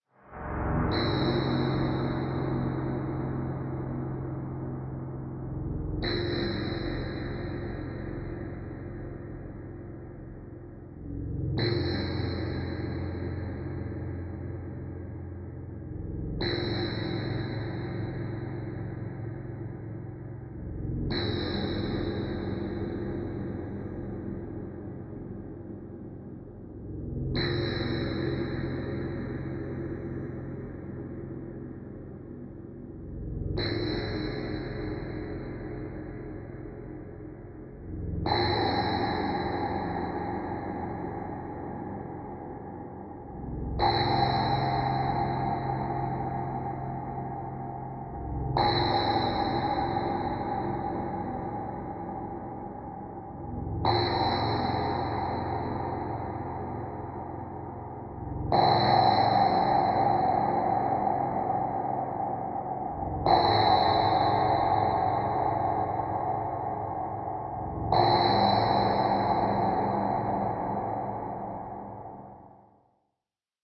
描述：最近用VirSyn Cube v1制作了各种声音。大多数即兴创作的无人机和短小的音乐作品可能用于背景视频声音或可能与其他声音混合。
标签： 合成器 冲击 无人驾驶飞机 黑暗 机械 冲击
声道立体声